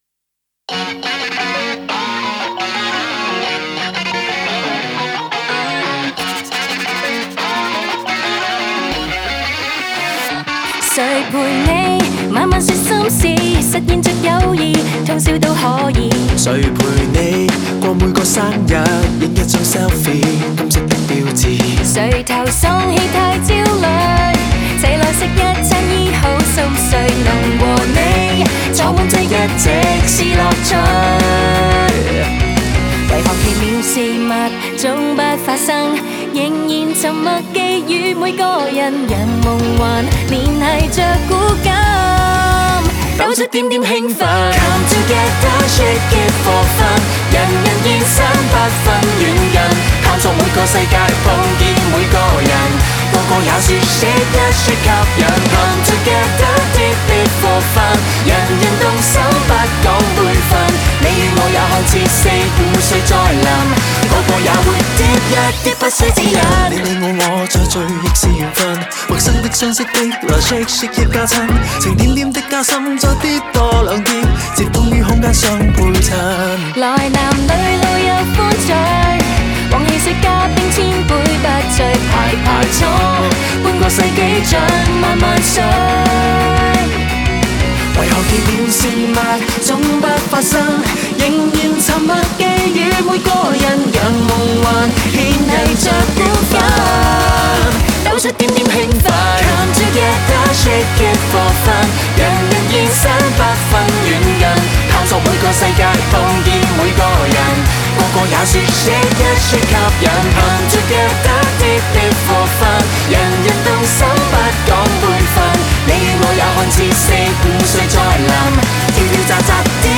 Ps：在线试听为压缩音质节选，体验无损音质请下载完整版
Backing Vocal